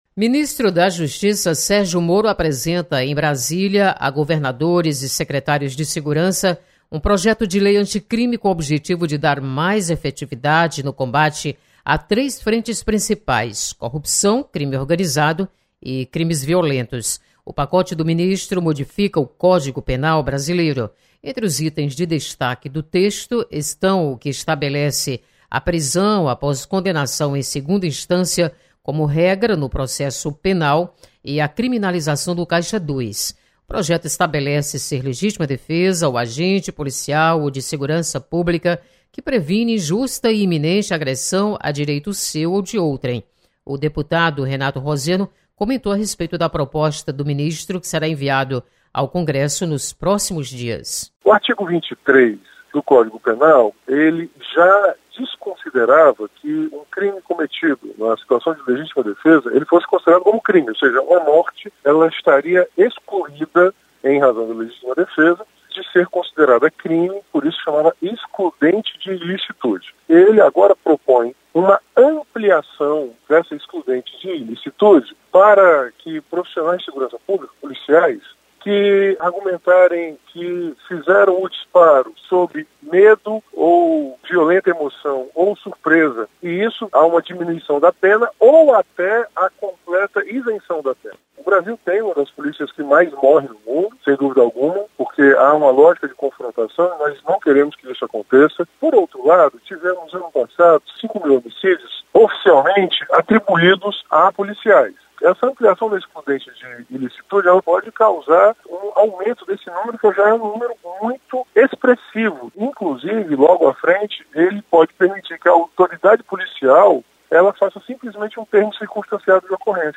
Deputados comentam sobre propostas anticrime apresentadas pelo ministro Sergio Moro.